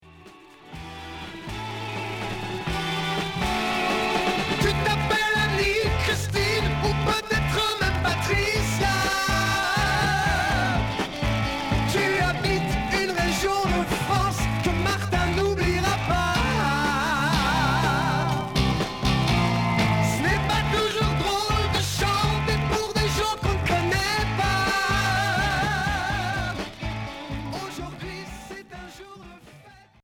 Pop Rock Dixième 45t retour à l'accueil